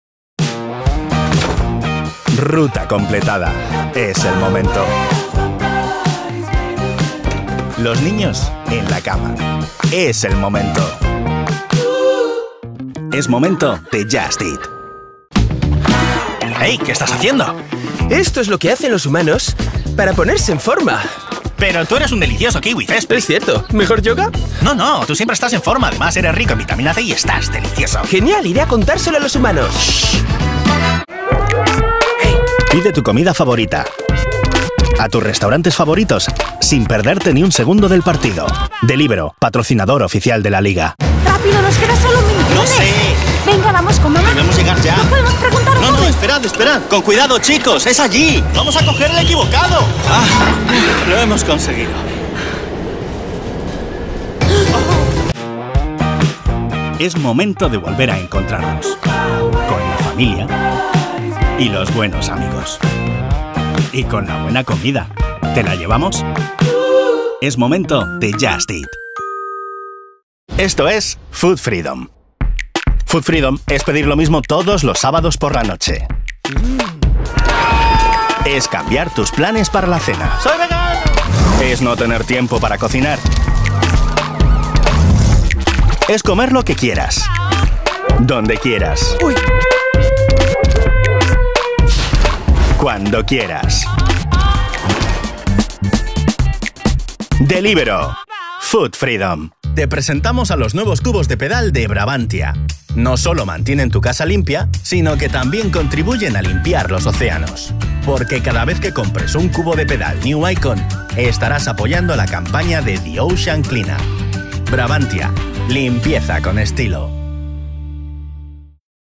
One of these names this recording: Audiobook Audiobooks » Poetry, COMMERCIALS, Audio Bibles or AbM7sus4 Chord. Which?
COMMERCIALS